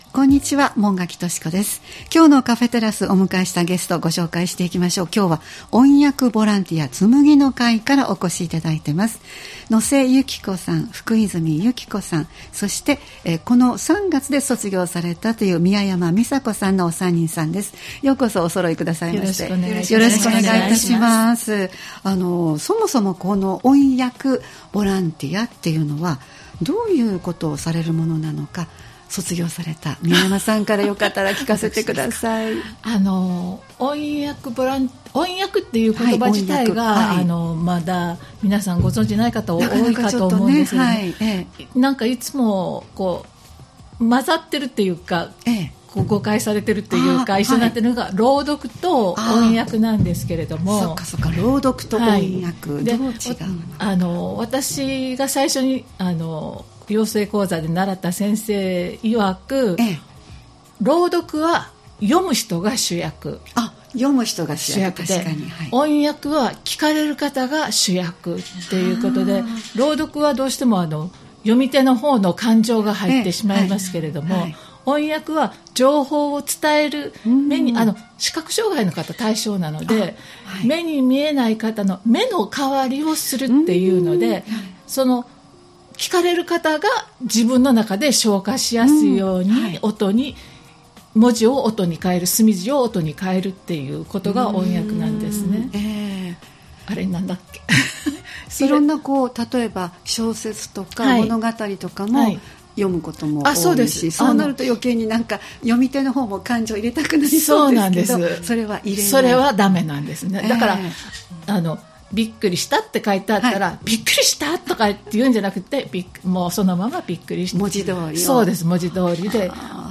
様々な方をスタジオにお迎えするトーク番組「カフェテラス」（再生ボタン▶を押すと放送が始まります）